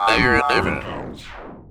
Techno / Voice
1 channel